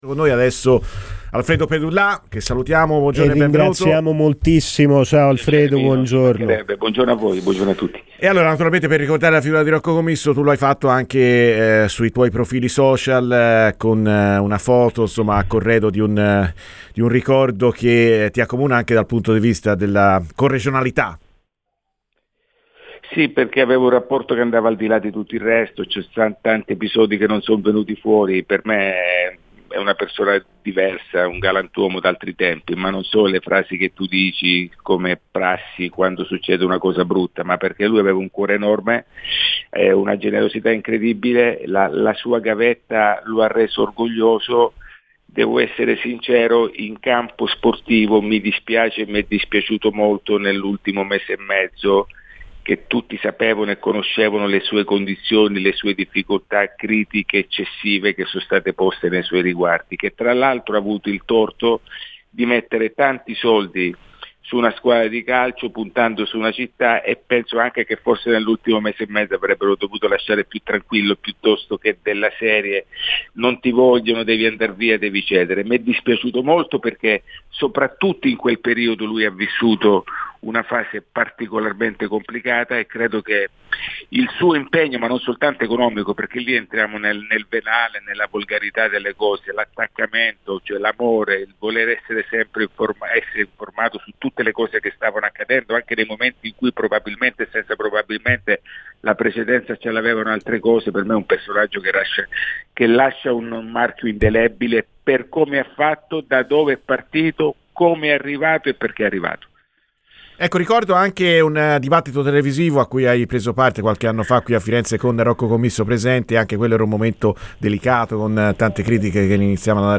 Alfredo Pedullà, giornalista e opionionista, è intervenuto a Radio FirenzeViola per ricordare la figura di Rocco Commisso: "Sì avevo un rapporto che andava al di là di tutto il resto.